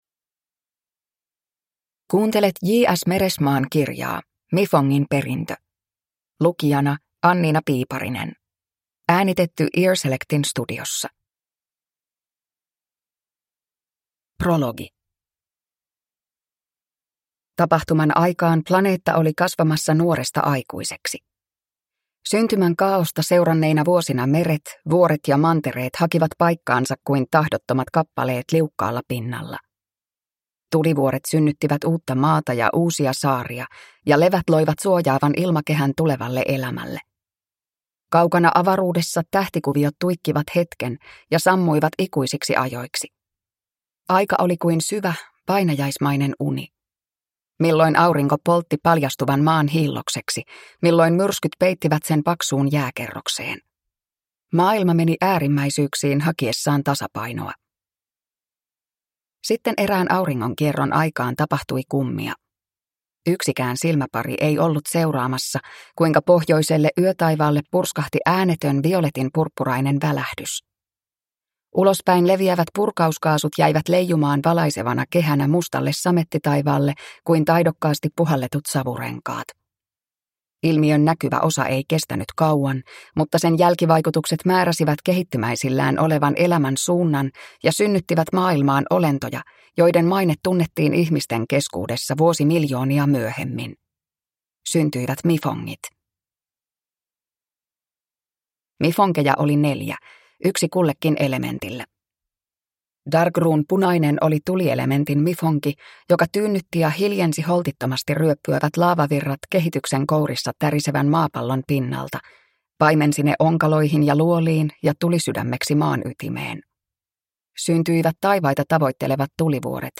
Mifongin perintö – Ljudbok